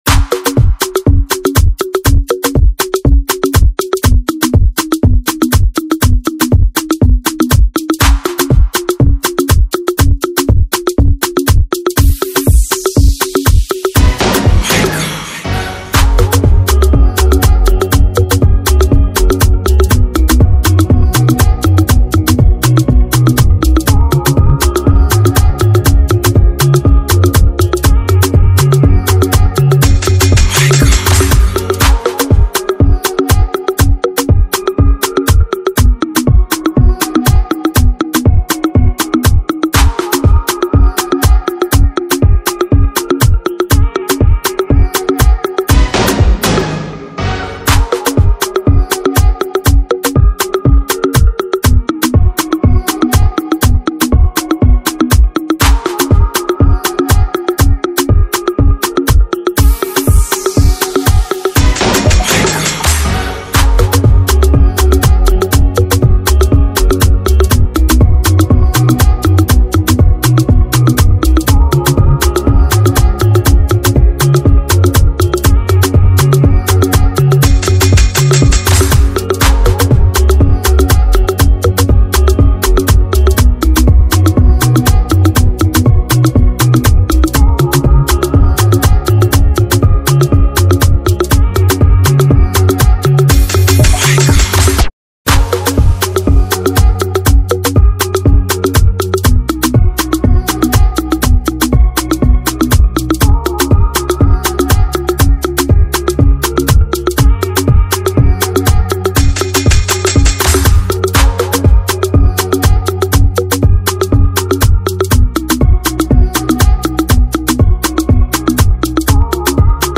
GENRE: Afro